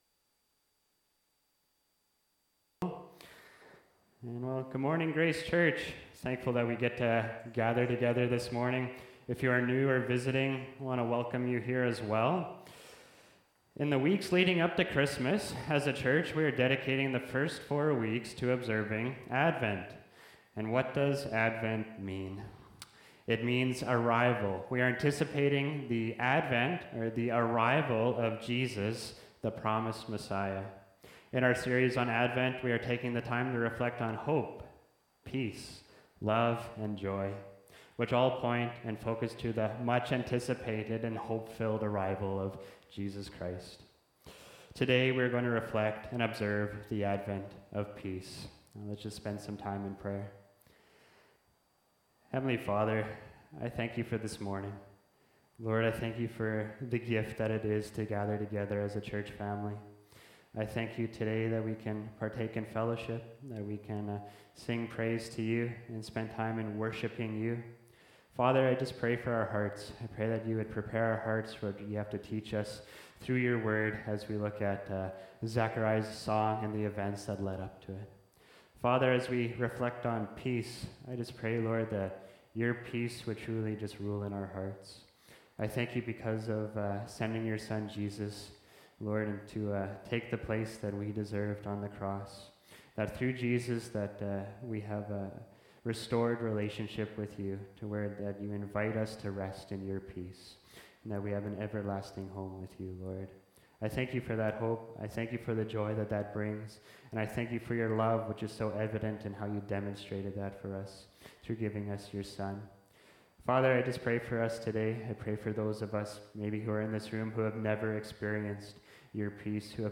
Dec 08, 2024 Zechariah’s Song (Luke 1:68-75) MP3 SUBSCRIBE on iTunes(Podcast) Notes Discussion Sermons in this Series Loading Discusson...